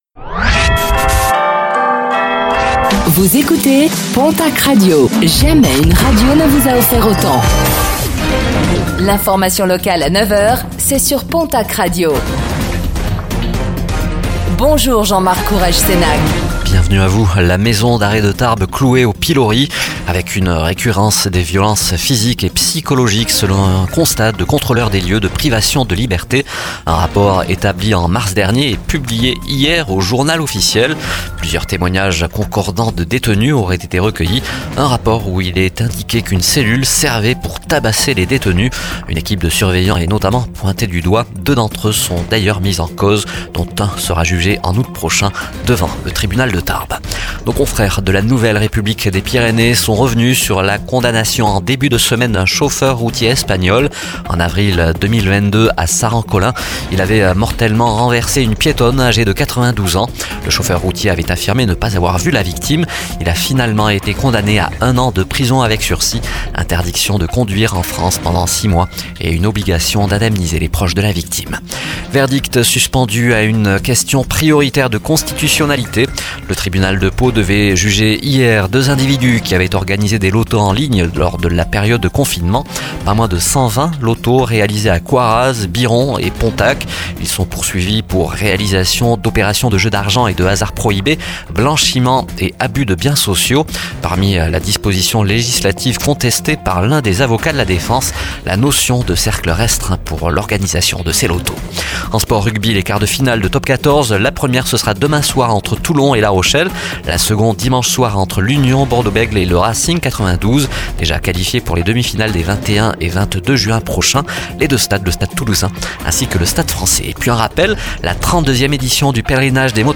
09:05 Écouter le podcast Télécharger le podcast Réécoutez le flash d'information locale de ce vendredi 14 juin 2024